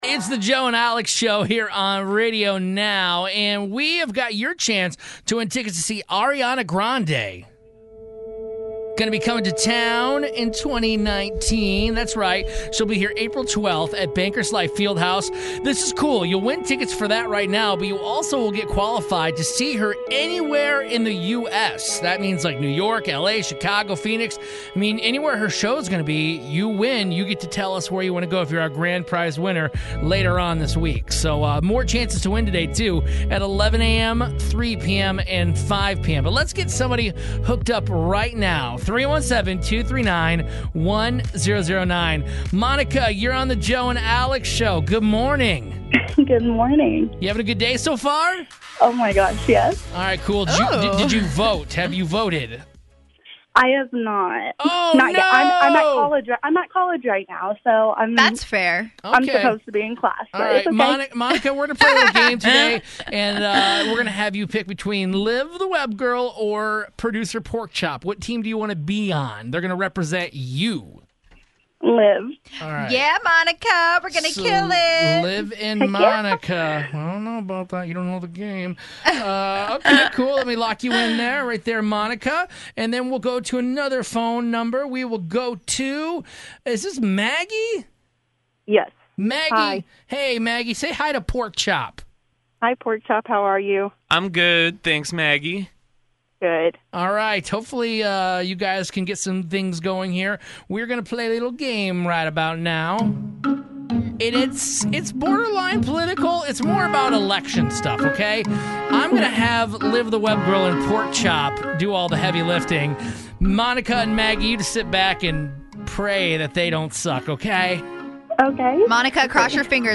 Lucky callers called up to try to win Ariana Grande tickets.